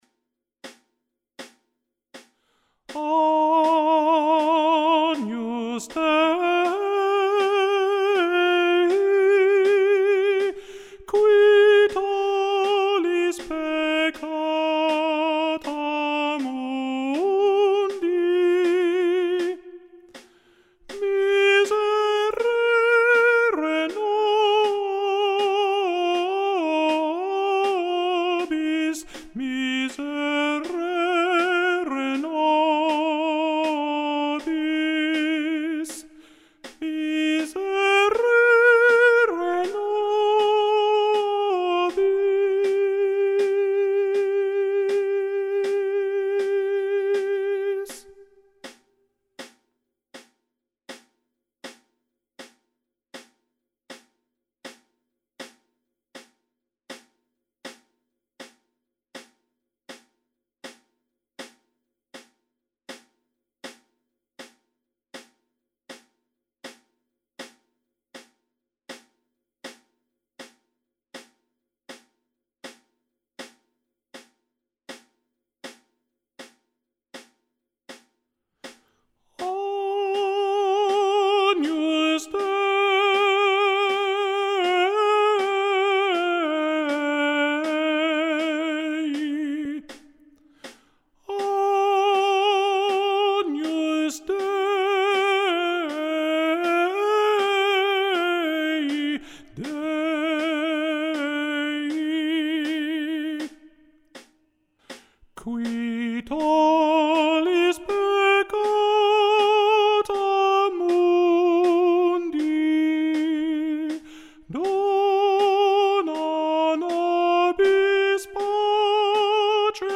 Agnus Dei – with REAL VOICES!!
We’ve decided to go all out and provide real voices on the parts for your listening and learning pleasure.
There is a click-track included in each voice part so you can follow the beat.
Agnus-Dei-Alto.mp3